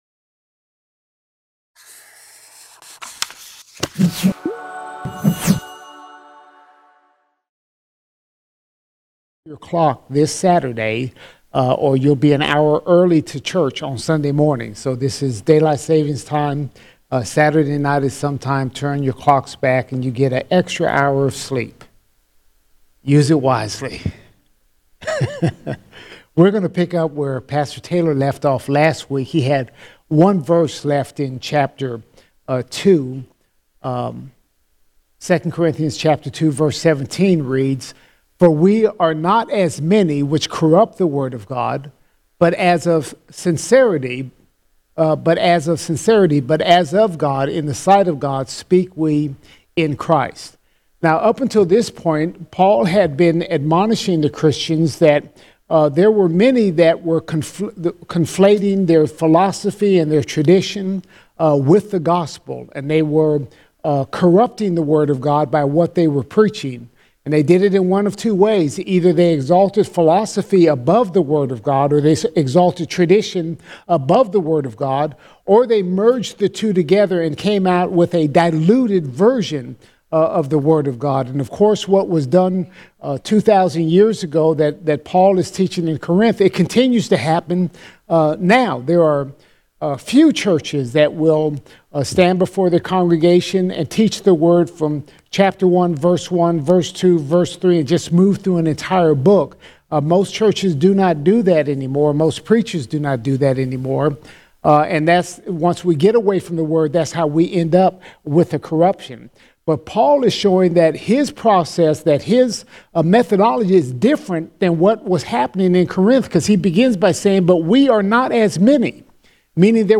29 October 2020 Series: 1 Corinthians All Sermons 1 Corinthians 3:1 to 3:23 1 Corinthians 3:1 to 3:23 Paul deals with Christian immaturity as the Corinthians fight over being with Apollos or Paul.